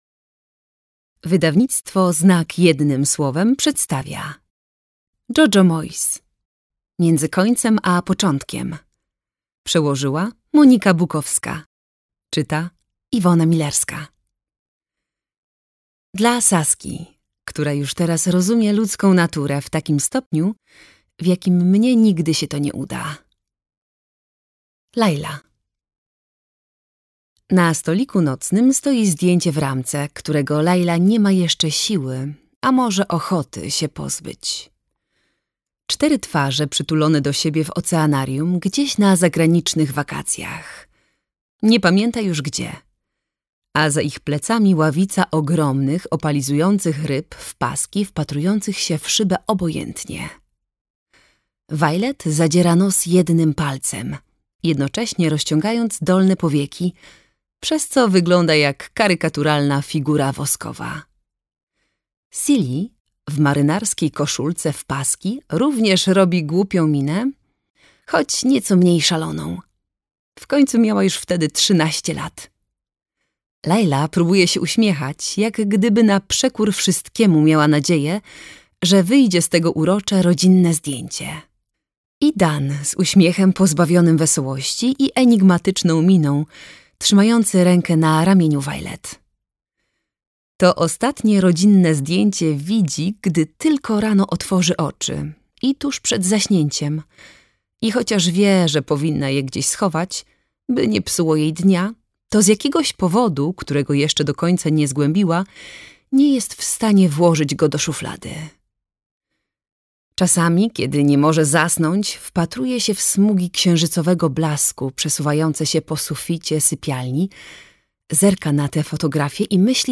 Między końcem a początkiem - Moyes, Jojo - audiobook